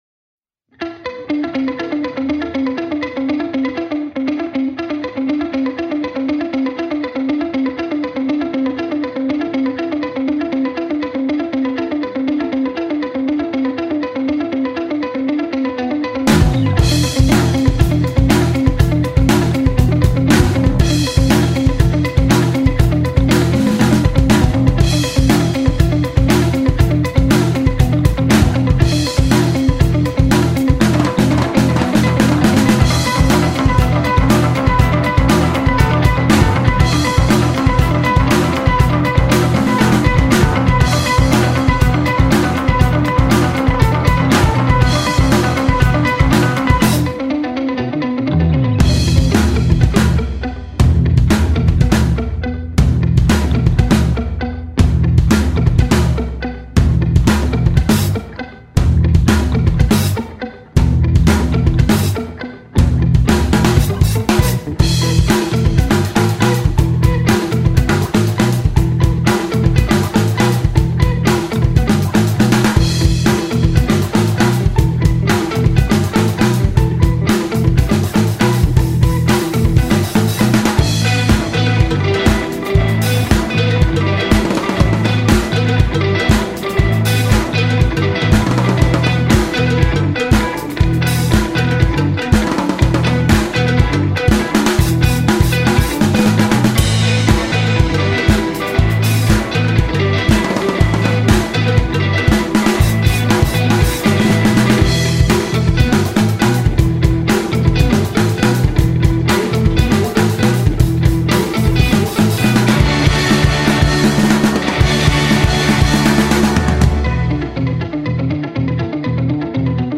three-piece math rock group